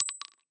bullet-metal-casing-drop-1.mp3